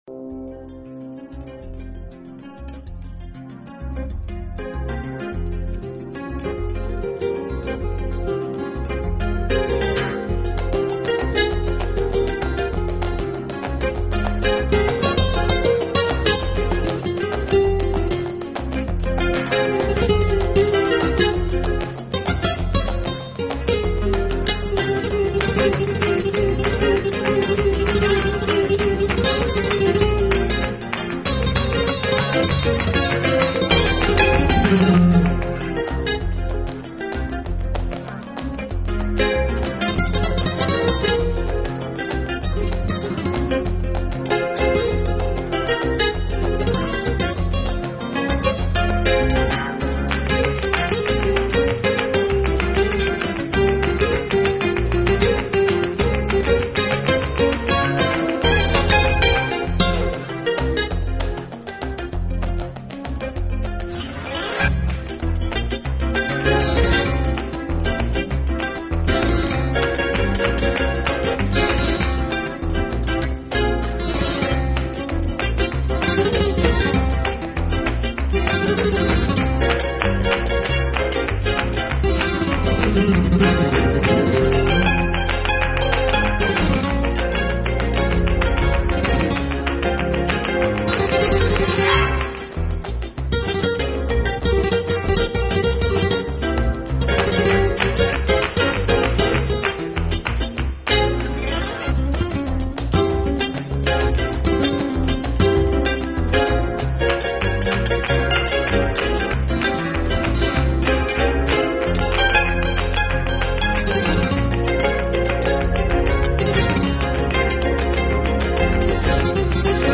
音乐类型：NewAge 新世纪
音乐风格：器乐